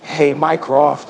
synthetic-wakewords
ovos-tts-plugin-deepponies_Kanye West_en.wav